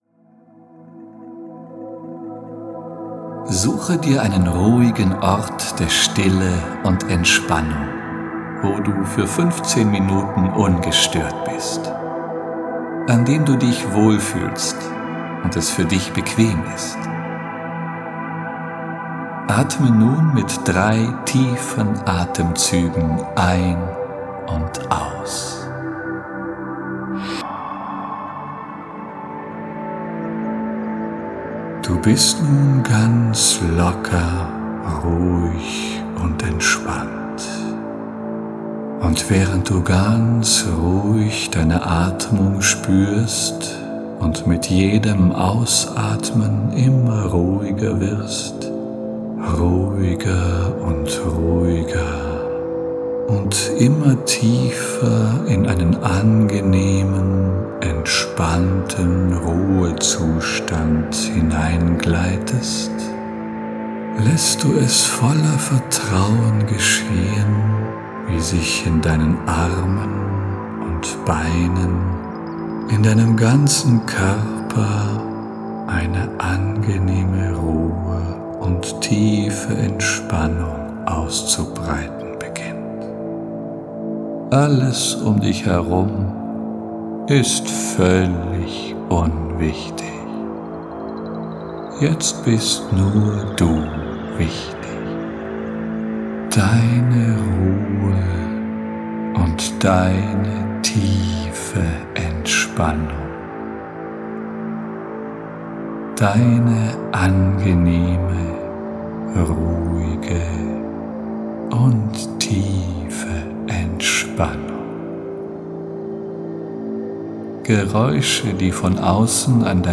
Genre: Meditation